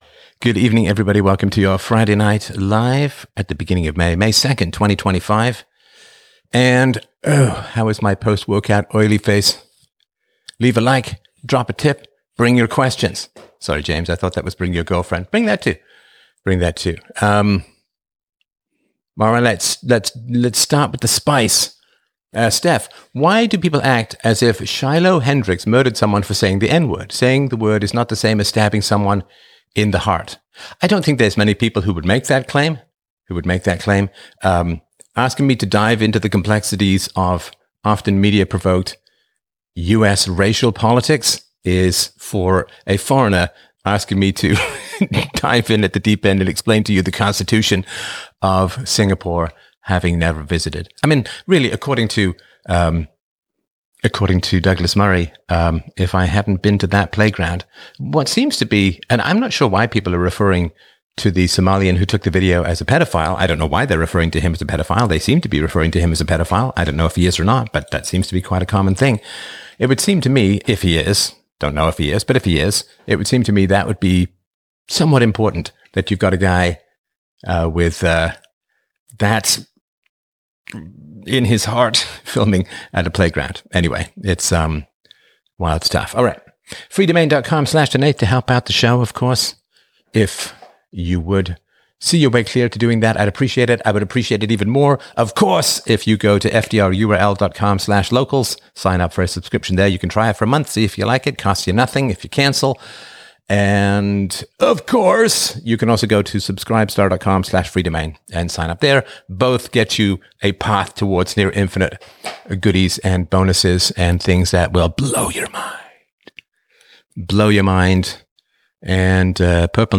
Sunday Morning Live 13 April 2025 In this episode, I explore the philosophy of self-defense in light of a recent stabbing incident involving a teenager, discussing Texas laws and the concept of "reasonable force."